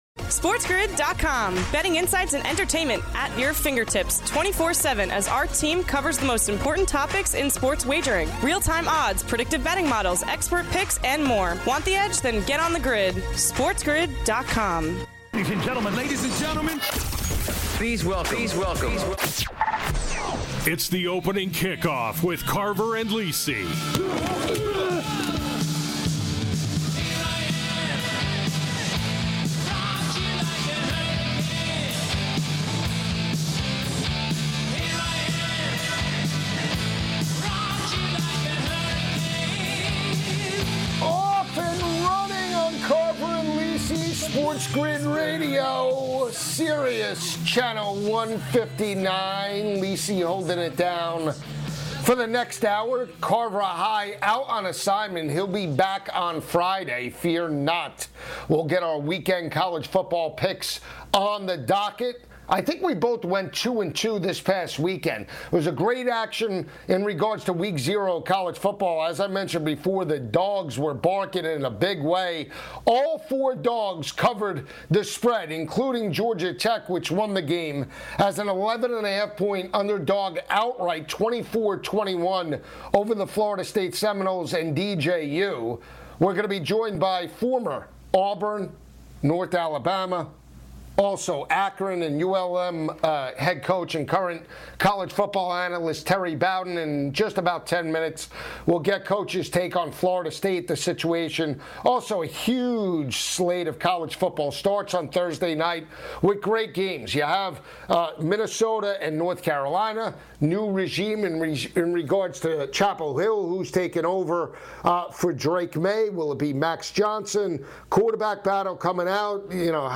Plus, Coach Terry Bowden joins to discuss the latest in college football!